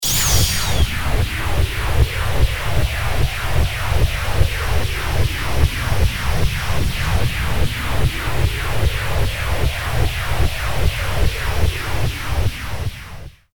戦闘 （163件）
気を溜める2.mp3